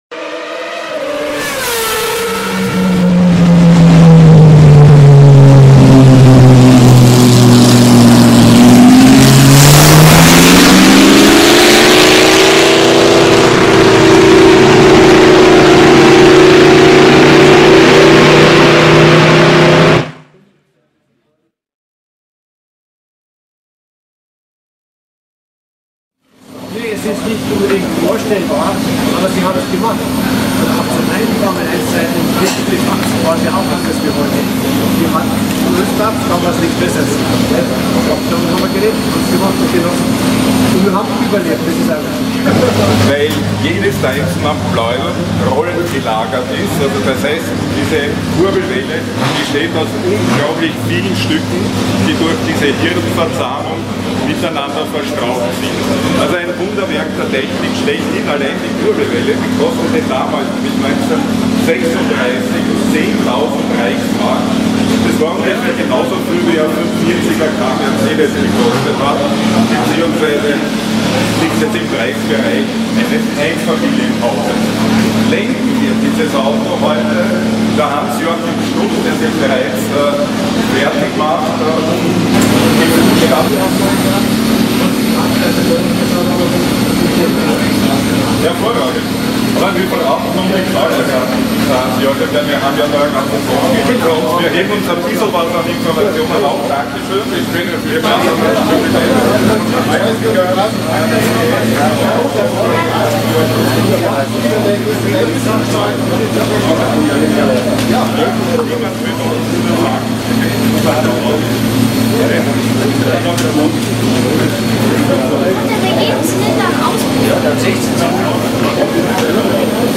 AUTO UNION 16 CYLINDER - EDELWEISS BERGPREIS ROSSFELD 2024 _ PURE SOUND ( FULL HD)